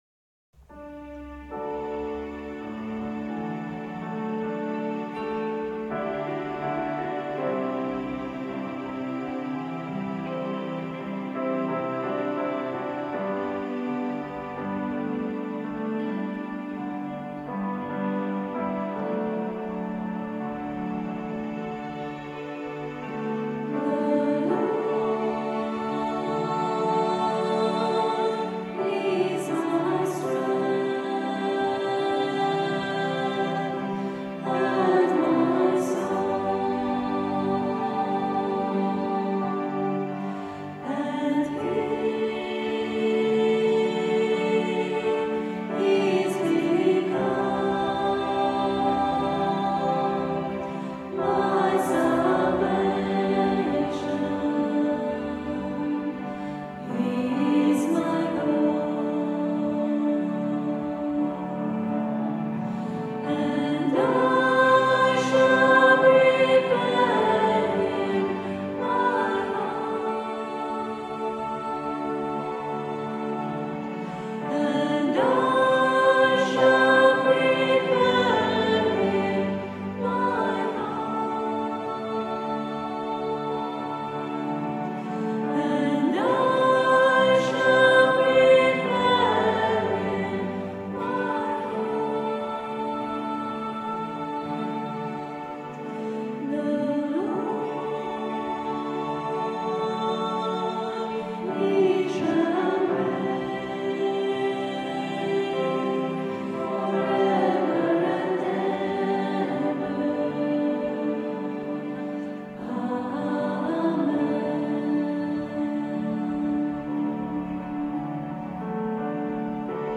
Recorded at rehearsal on 21st September 2004 in digital stereo using Sony minidisc.